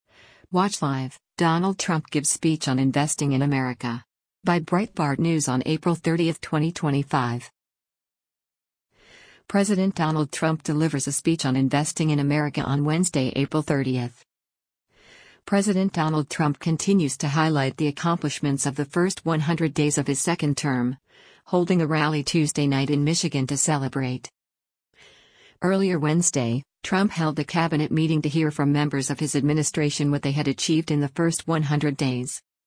President Donald Trump delivers a speech on investing in America on Wednesday, April 30.